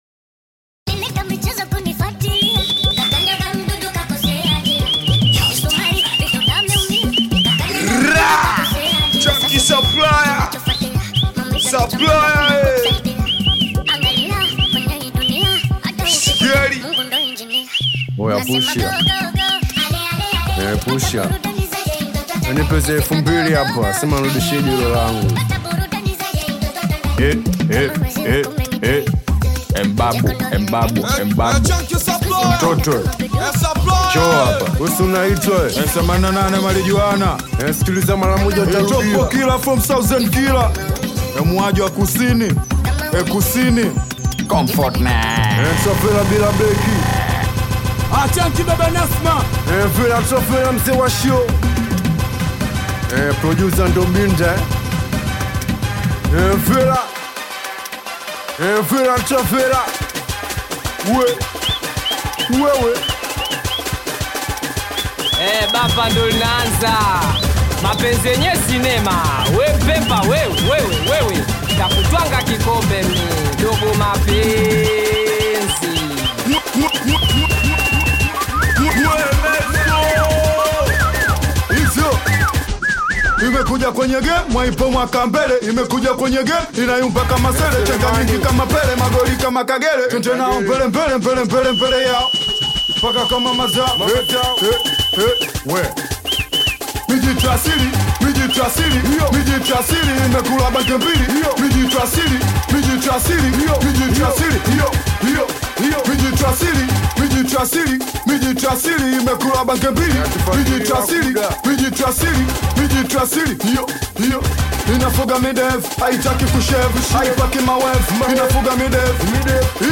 A Captivating Fusion of Rhythms and Cultures
infectious beats
soulful vocals